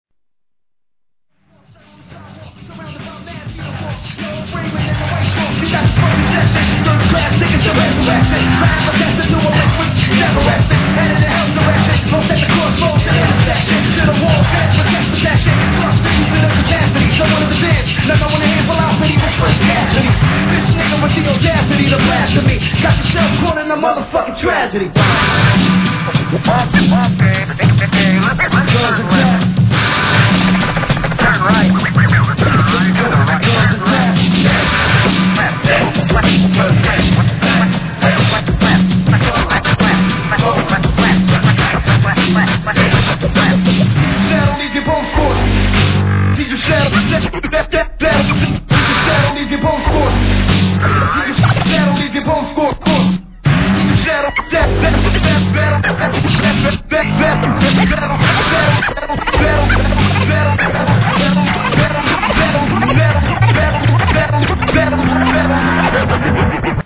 HIP HOP/R&B
彼独自の世界感を見事表現したロンドンでのLIVEを収録です！！